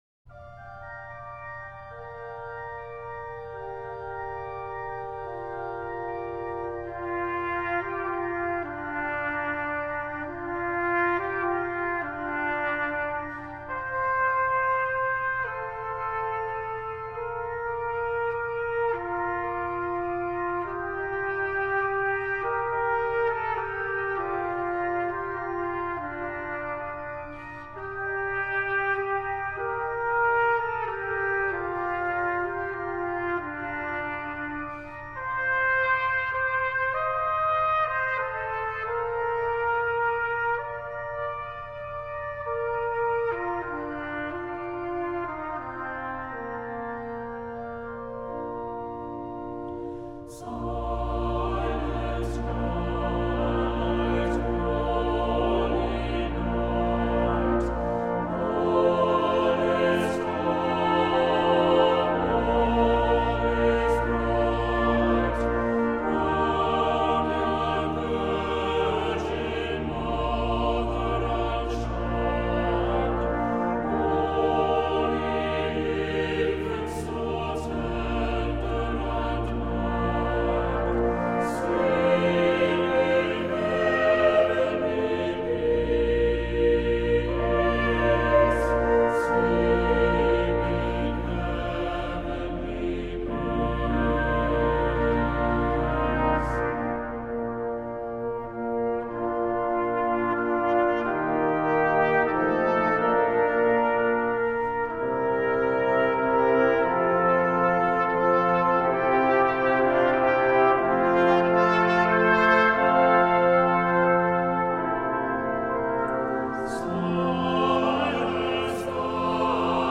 Accompaniment:      Organ
Music Category:      Choral